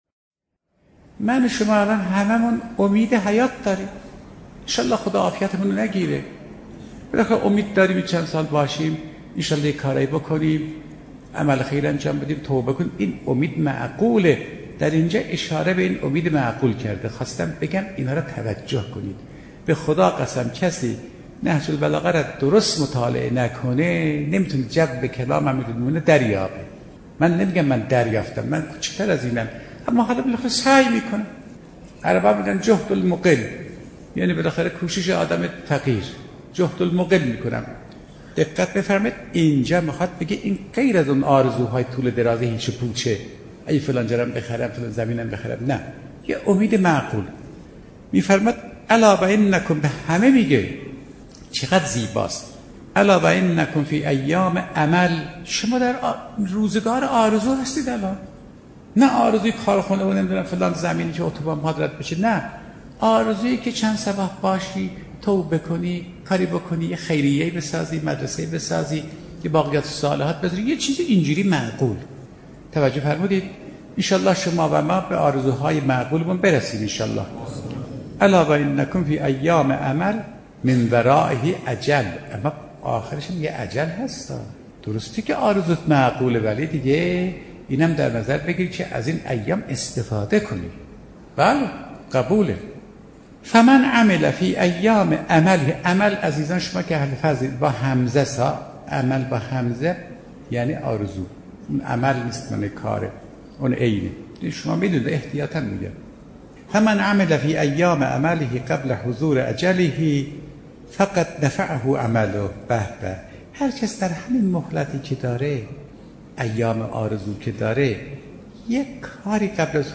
گزیده ای از سخنرانی استاد فاطمی نیا پیرامون استفاده از فرصت باقی مانده عمر را می شنوید.